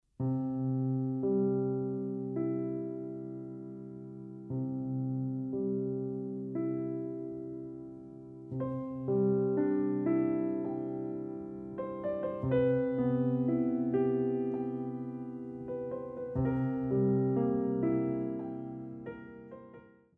Révérence